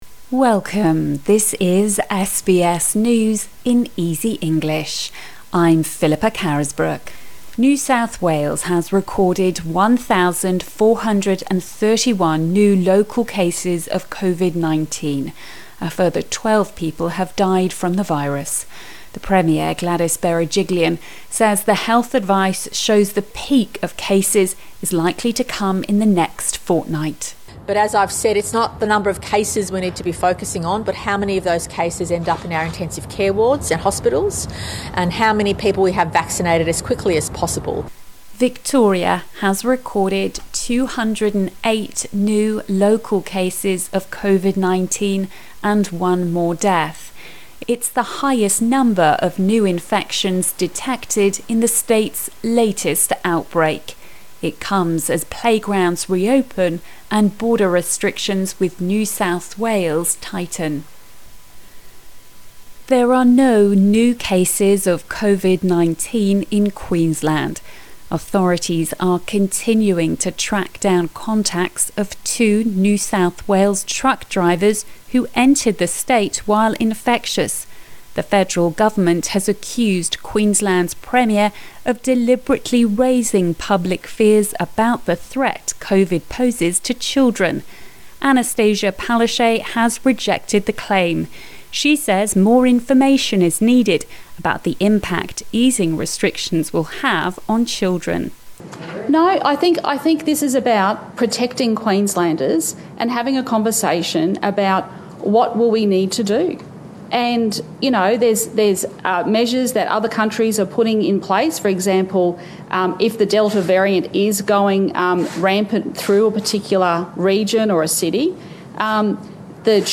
A daily 5-minute news wrap for English learners.